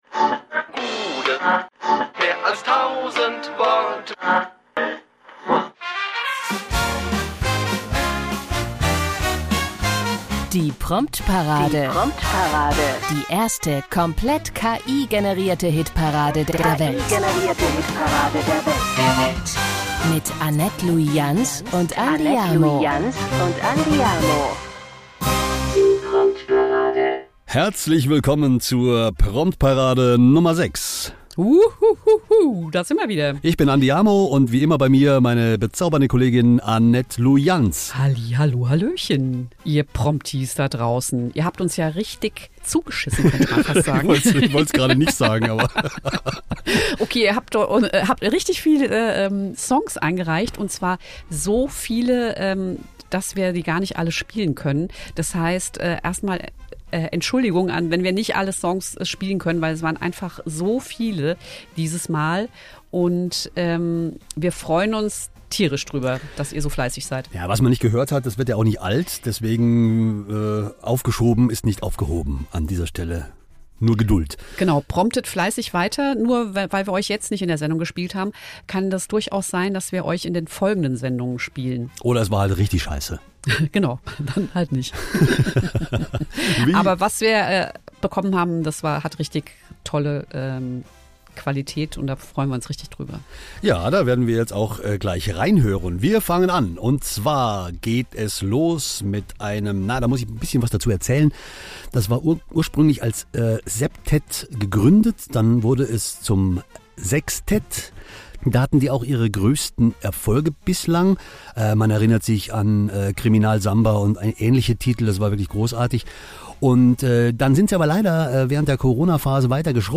Alle Songs wurden zumindest komplett von einer künstlichen Intelligenz umgesetzt. Es wurde weder etwas eingespielt, noch eingesungen.